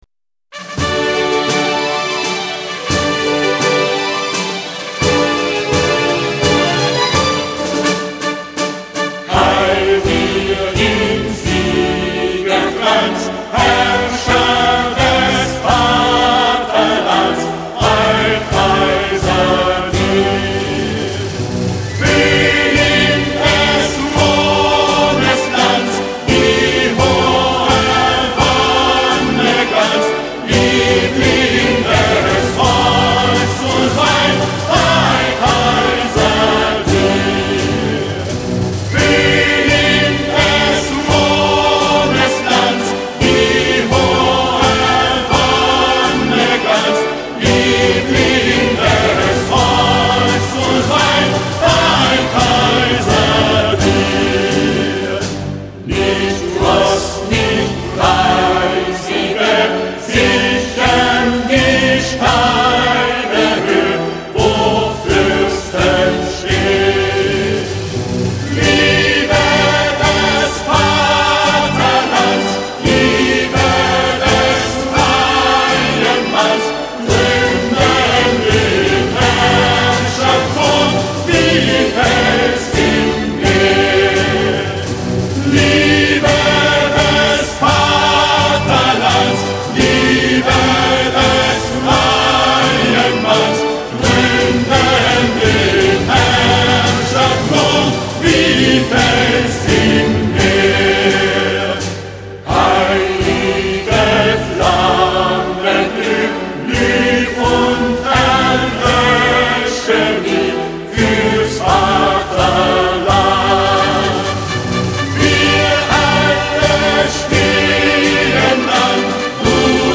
Именно в честь последнего оркестр играл "Боже, храни королеву" во второй раз.